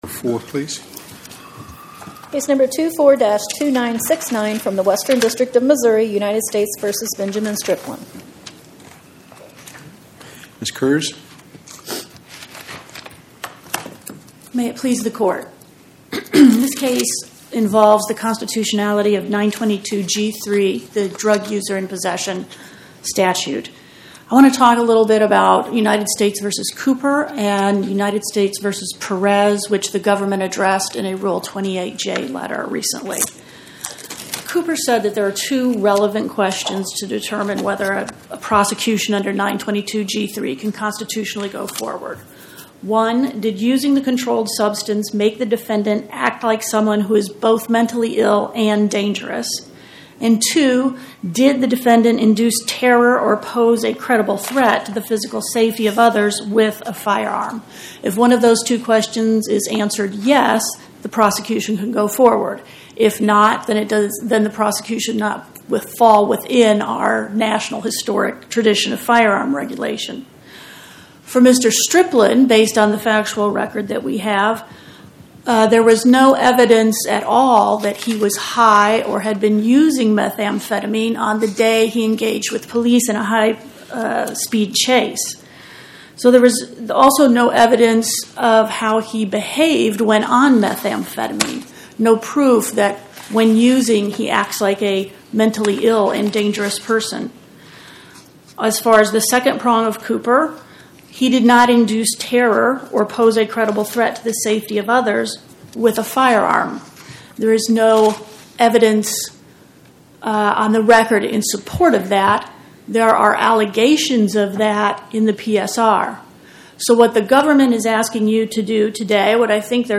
Oral argument argued before the Eighth Circuit U.S. Court of Appeals on or about 09/16/2025